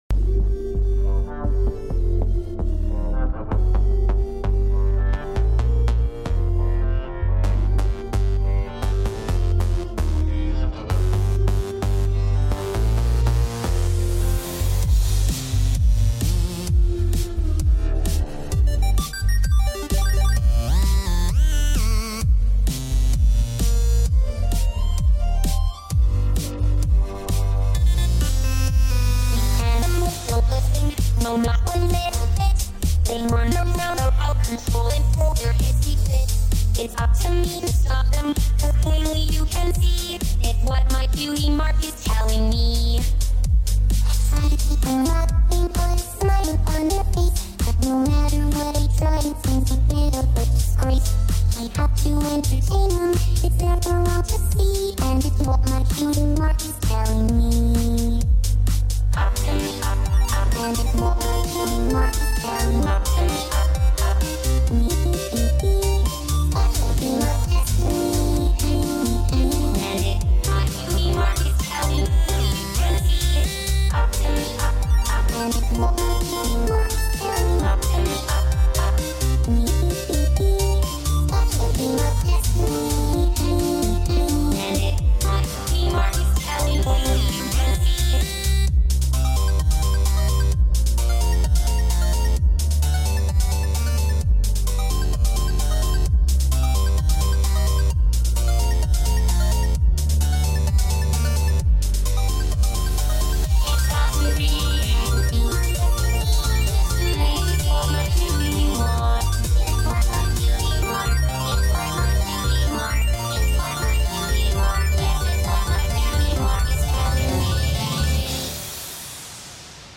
Second song using fl studio.